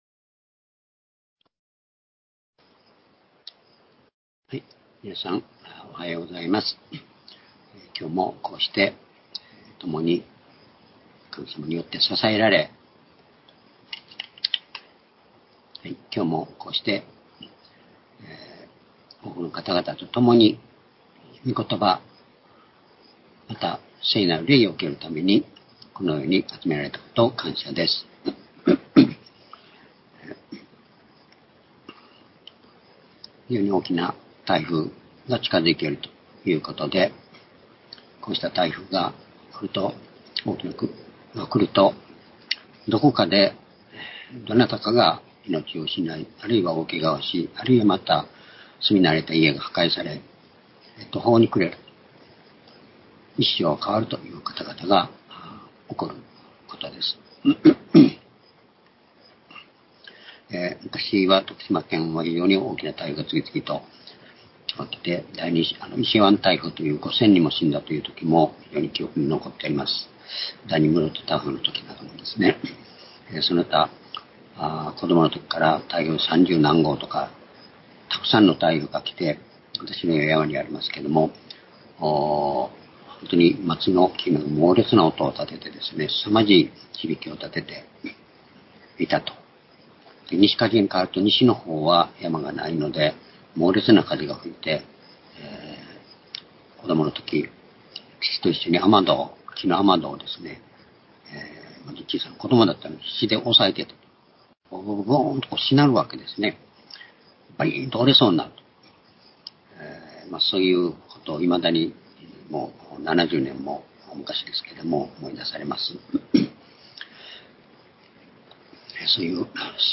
主日礼拝日時 2022年9月18日（主日礼拝） 聖書講話箇所 「闇から目指す地へ」（「海の上を歩く」（その２） ヨハネ6章18節～21節 ※視聴できない場合は をクリックしてください。